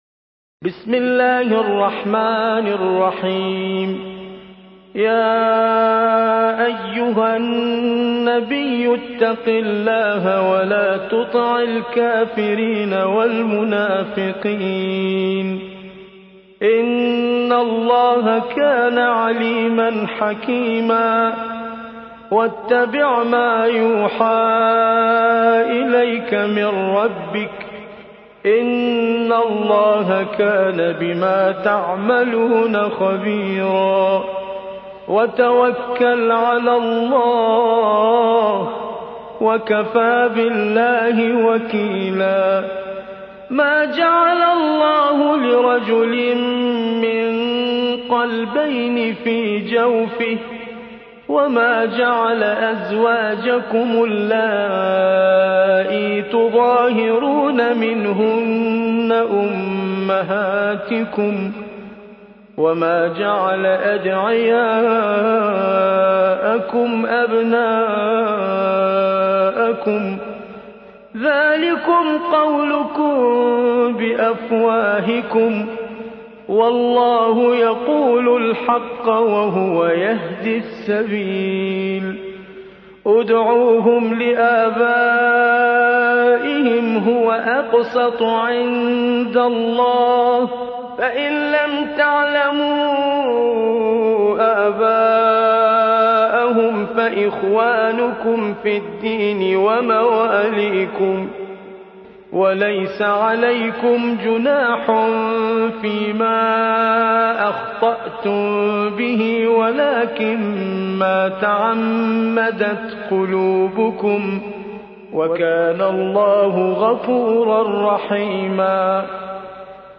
33. سورة الأحزاب / القارئ